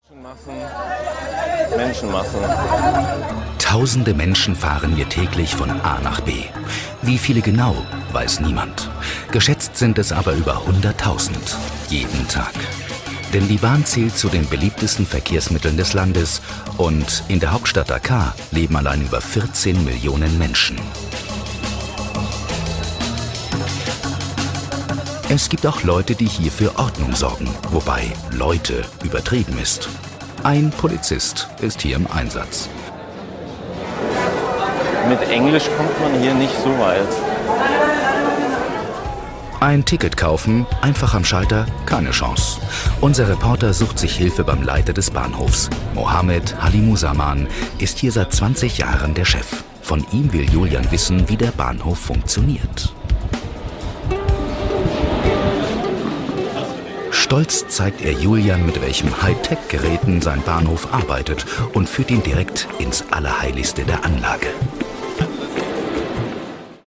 Sprechprobe: Sonstiges (Muttersprache):
TV-Doku ProSieben Galileo.mp3